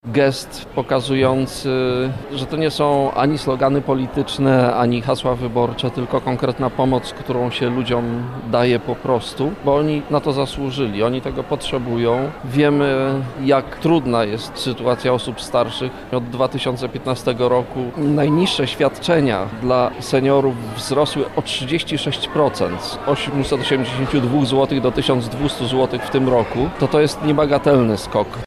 Rządowy pakiet działań dedykowanych seniorom został przedstawiony w Lublinie podczas Międzypokoleniowego Dnia Aktywności. W pakiecie jest program Senior +, dzięki któremu rozbudowywana jest oferta zajęć dla osób starszych oraz trzynasta emerytura.